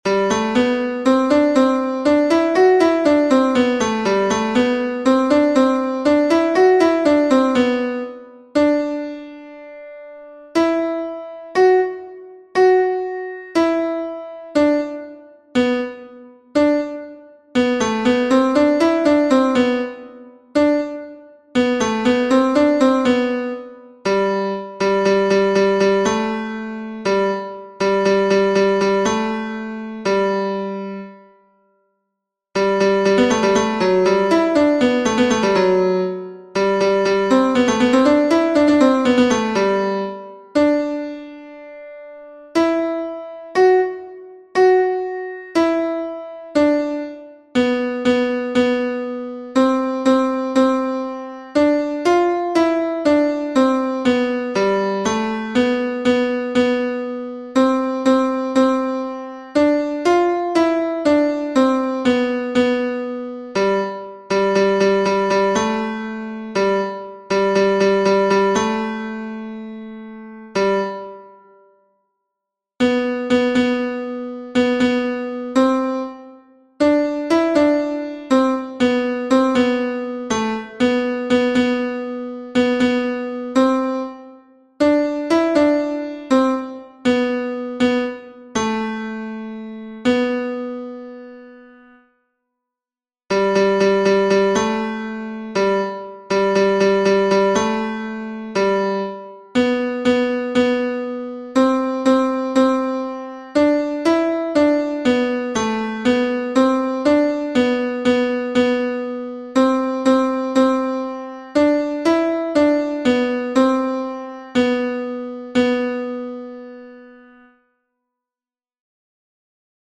tenors-mp3 23 juin 2021